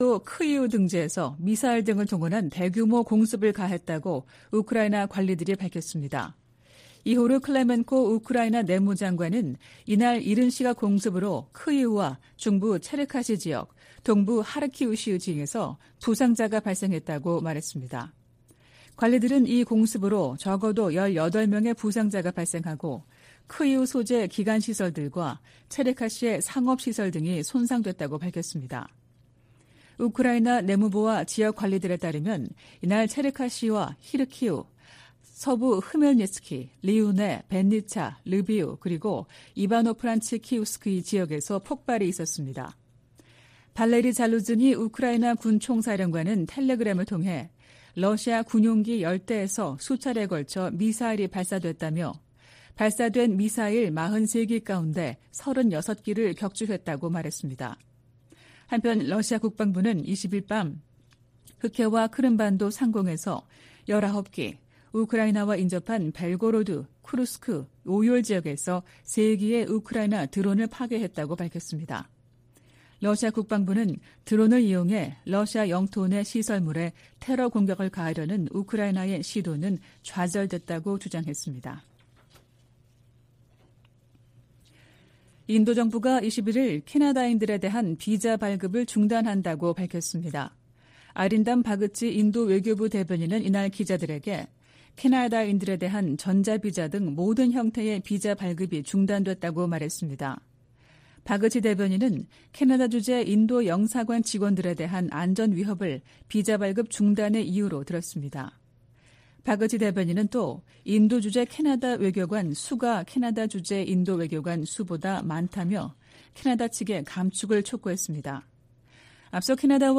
VOA 한국어 '출발 뉴스 쇼', 2023년 9월 22일 방송입니다. 윤석열 한국 대통령이 유엔총회 연설에서 북한의 핵과 탄도미사일 개발이 세계 평화에 대한 중대한 도전이라고 규탄했습니다.